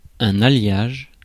Ääntäminen
Ääntäminen France: IPA: [ɛ̃n‿a.ljaʒ] Tuntematon aksentti: IPA: /a.ljaʒ/ Haettu sana löytyi näillä lähdekielillä: ranska Käännös Ääninäyte Substantiivit 1. alloy US 2. alloying Suku: m .